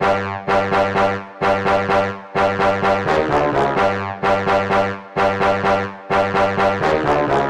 描述：刷靴